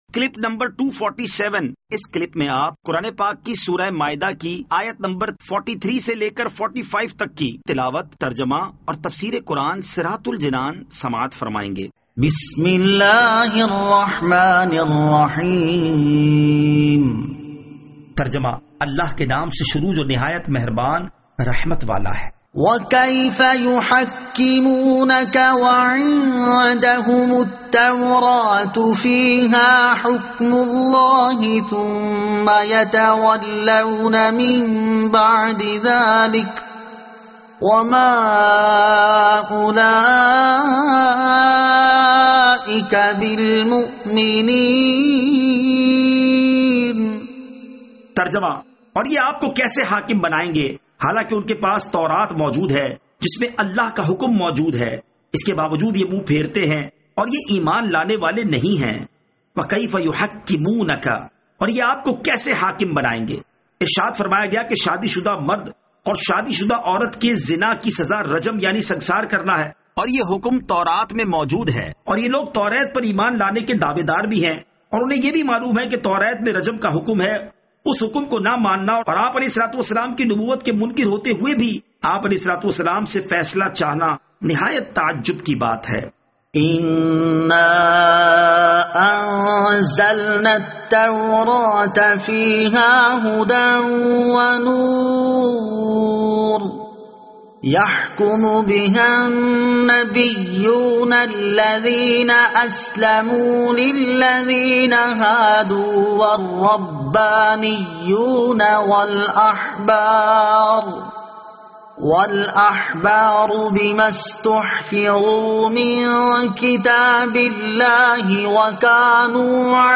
Surah Al-Maidah Ayat 43 To 45 Tilawat , Tarjama , Tafseer